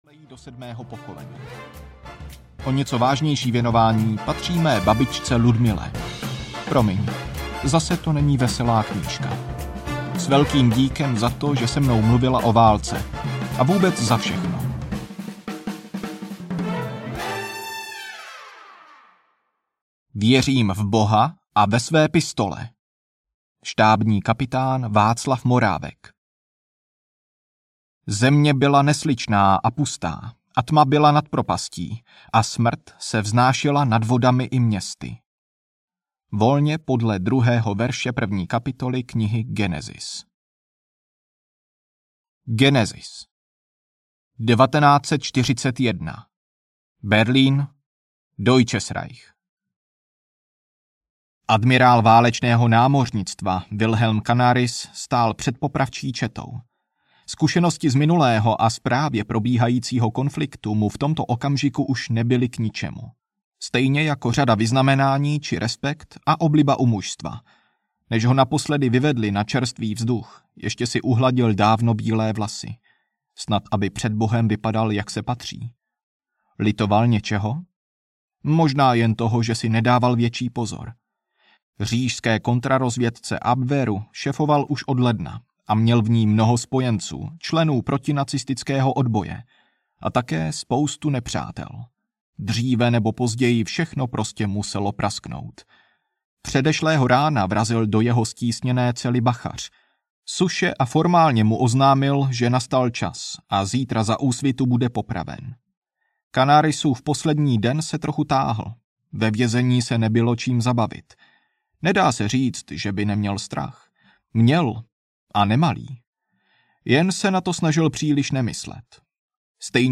Čokoláda pro wehrmacht audiokniha
Ukázka z knihy